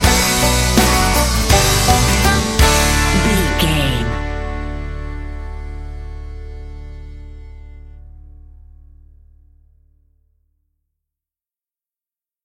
Ionian/Major
D
drums
electric piano
electric guitar
bass guitar
banjo
Pop Country
country rock
bluegrass
happy
uplifting
driving
high energy